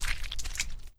Blood_Splatter_Large_SFX.wav